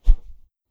Close Combat Swing Sound 3.wav